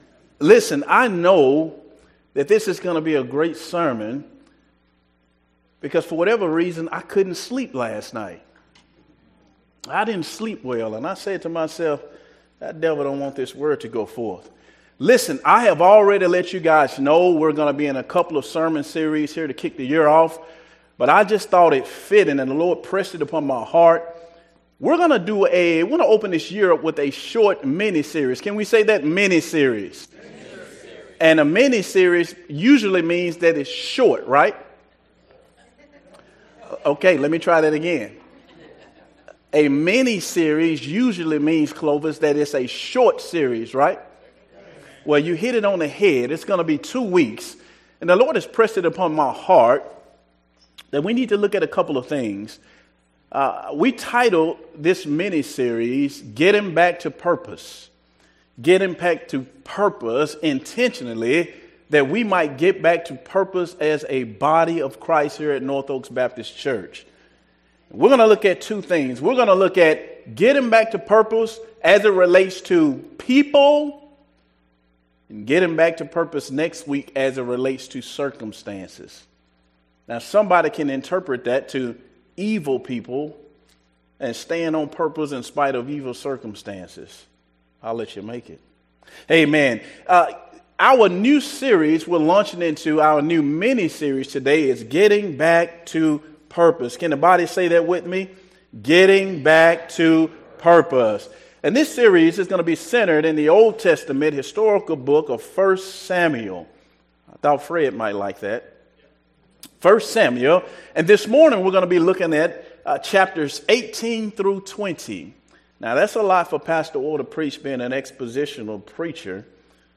Sunday Sermons from North Oaks Baptist Church in Spring, TX